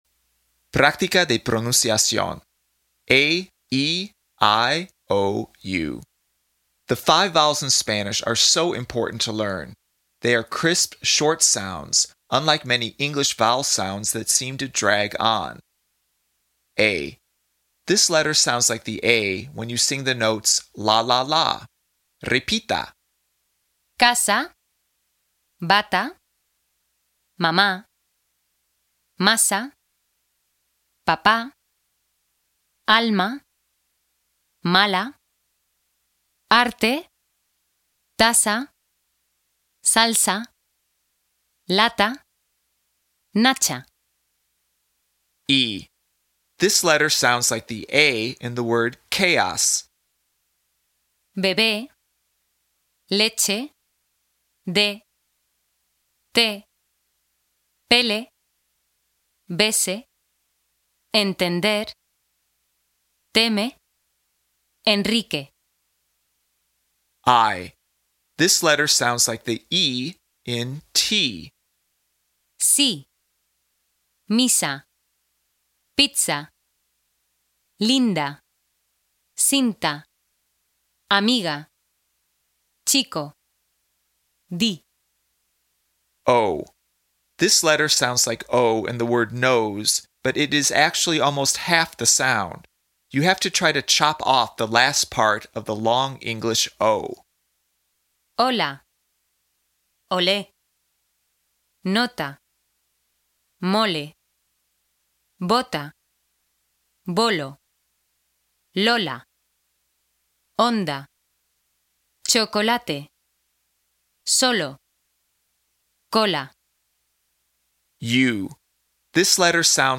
PRÁCTICA DE PRONUNCIACIÓN
They are crisp, short sounds, unlike many English vowel sounds that seem to drag on.
Once again, the sound is shorter and crisper.